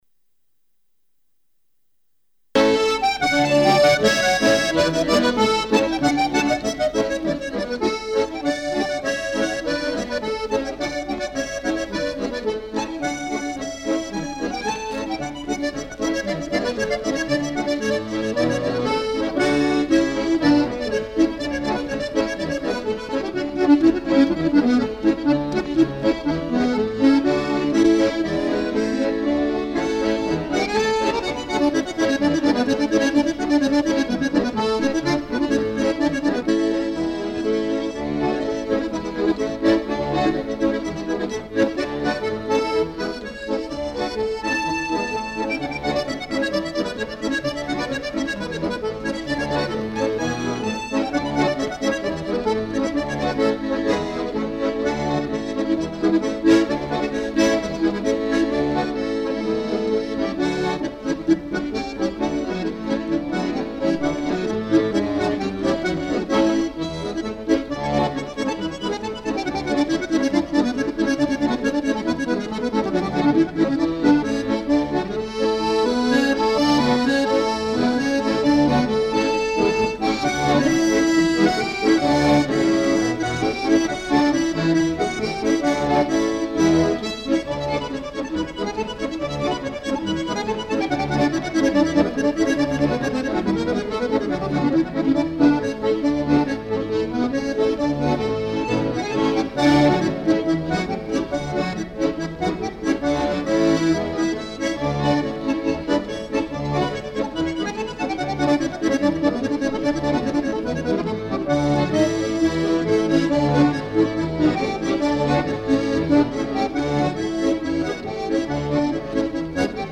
гармоника
баян